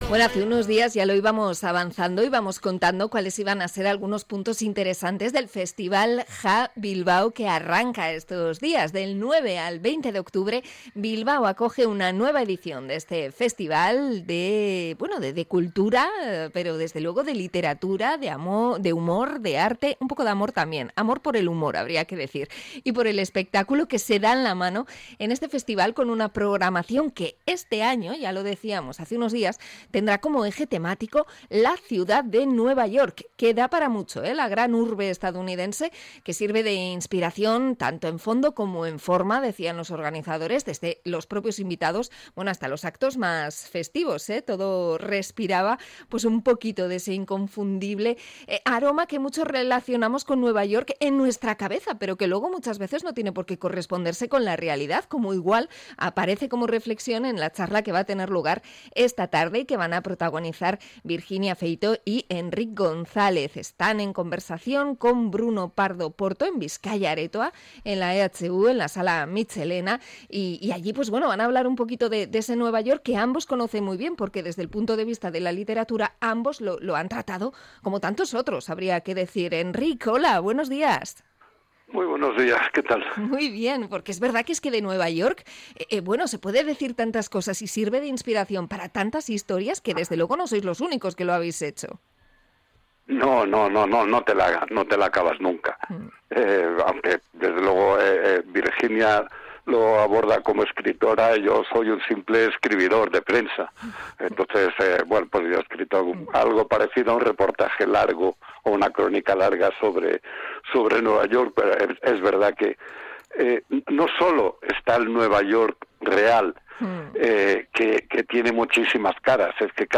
Entrevista al periodista Enric González sobre Nueva York